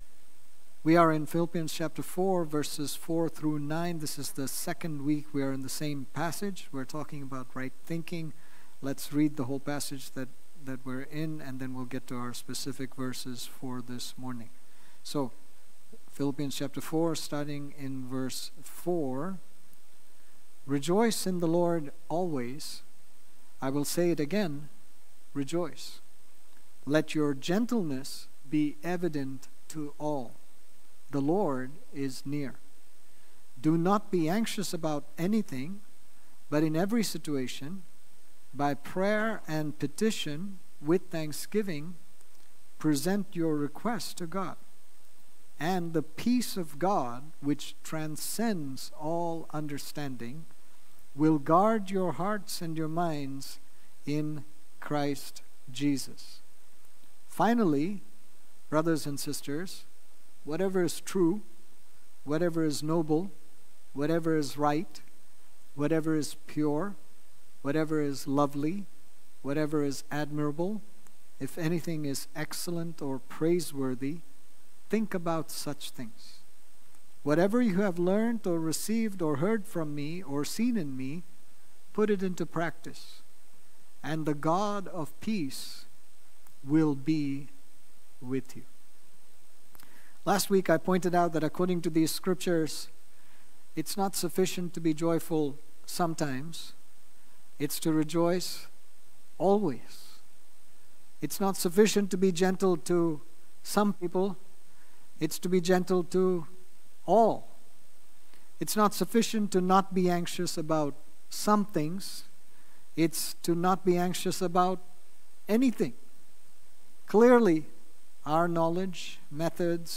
Sermons | New Life Fellowship Church